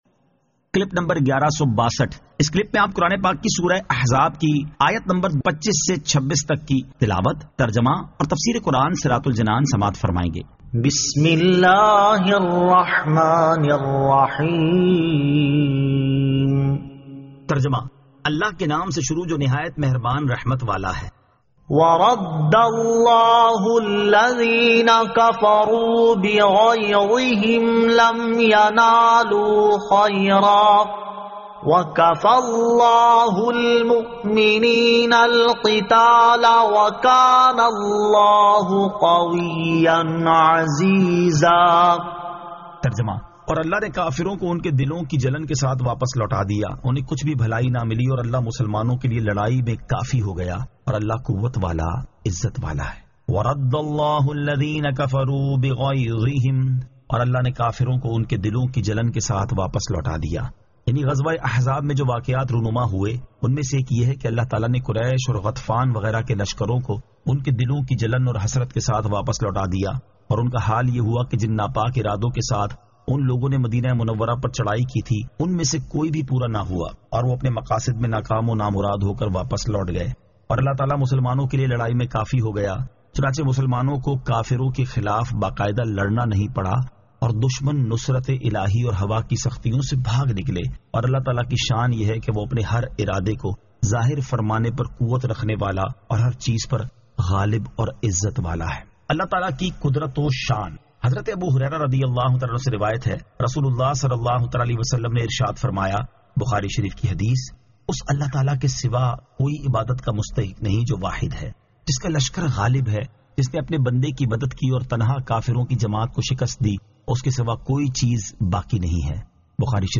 Surah Al-Ahzab 25 To 26 Tilawat , Tarjama , Tafseer